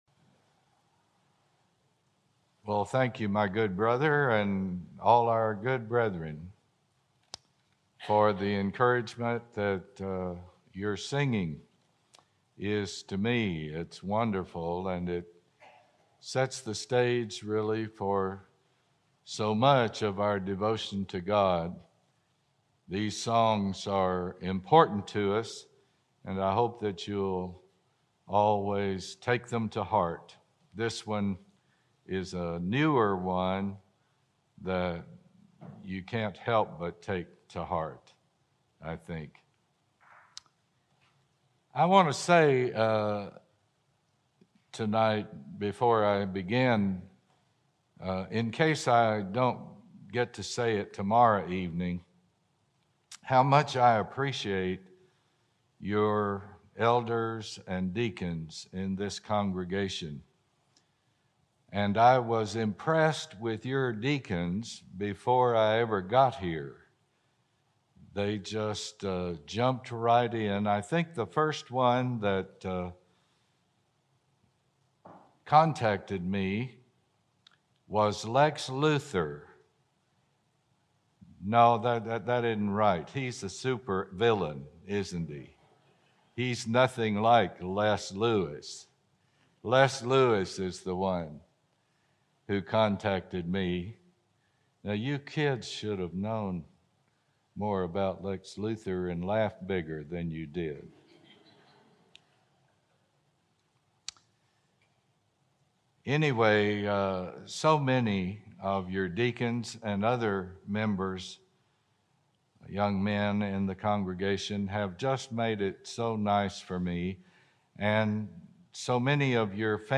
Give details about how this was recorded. Part 6 of our 7-part gospel meeting